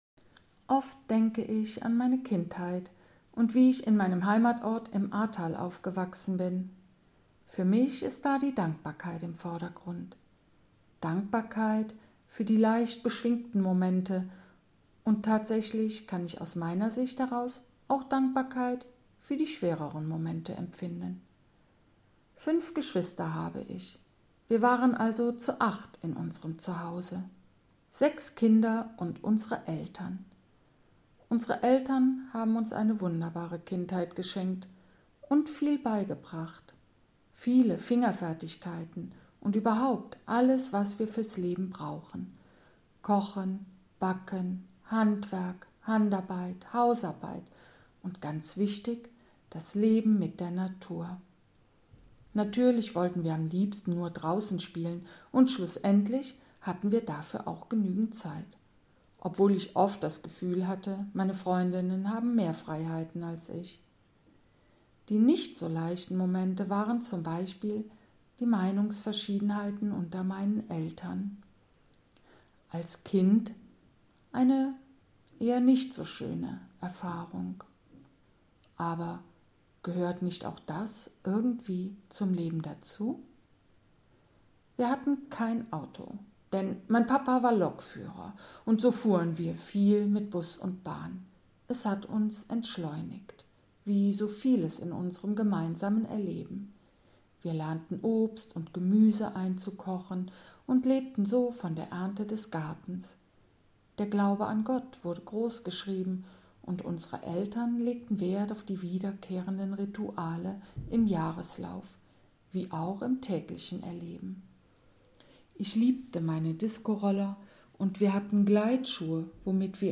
Bei den Aufnahmen, die oftmals in den Räumen der Waschbar stattfanden, ging es nicht um Perfektion, sondern um das wirkliche Leben. So begleiten schon mal Kirchenglocken oder auch Baustellengeräusche das gesprochene Wort.